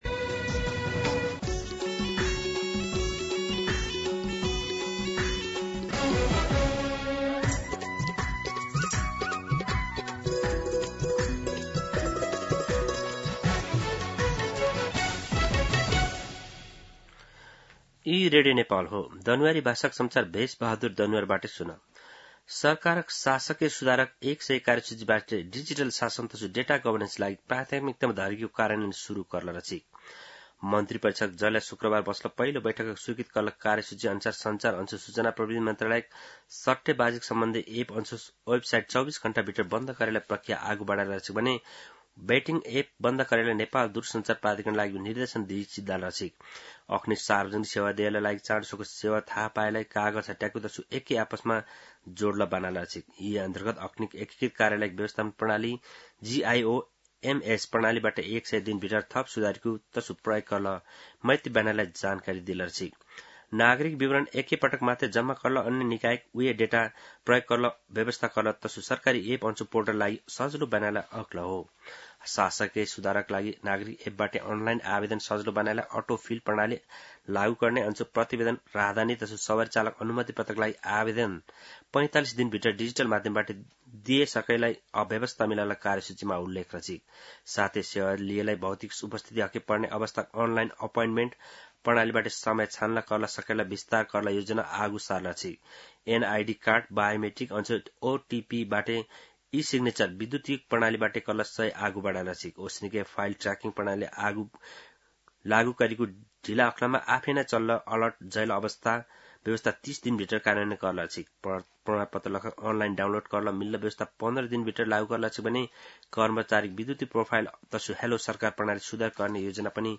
दनुवार भाषामा समाचार : १७ चैत , २०८२
Danuwar-News-12-17.mp3